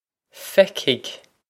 Feicfidh Fec-hig
Pronunciation for how to say
This is an approximate phonetic pronunciation of the phrase.